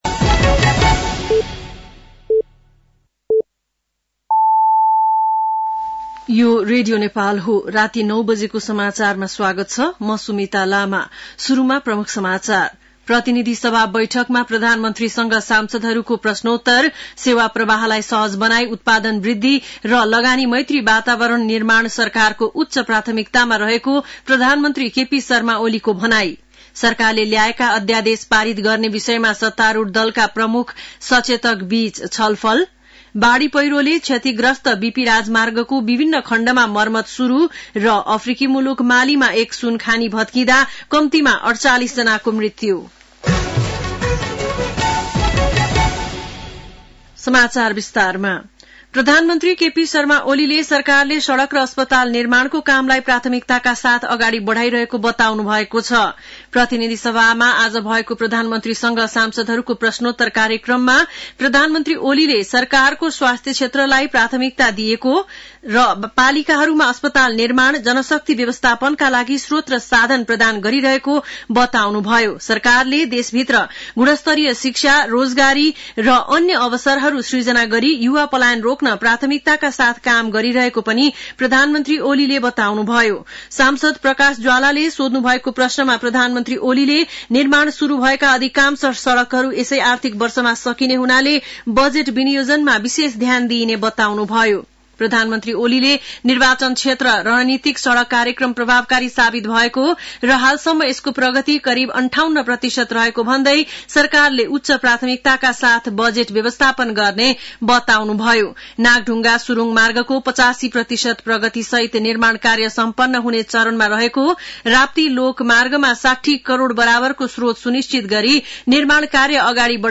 An online outlet of Nepal's national radio broadcaster
बेलुकी ९ बजेको नेपाली समाचार : ५ फागुन , २०८१
9-pm-nepali-news-11-04.mp3